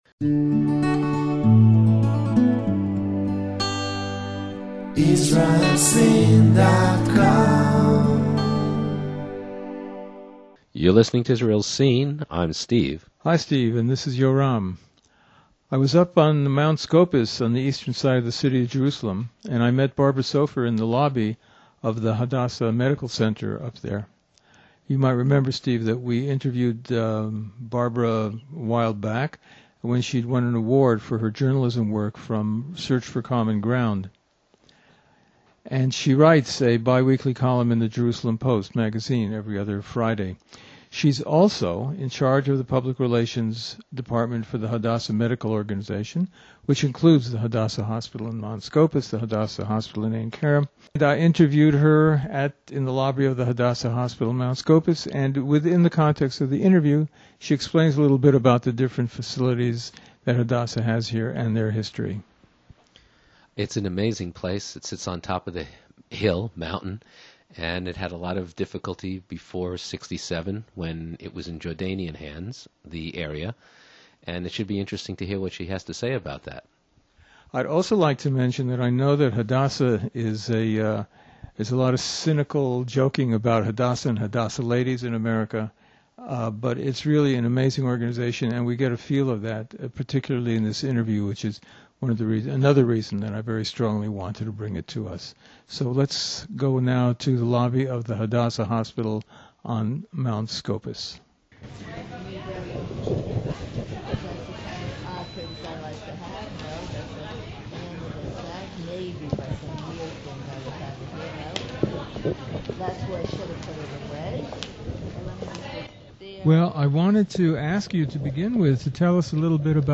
We have this wonderful interview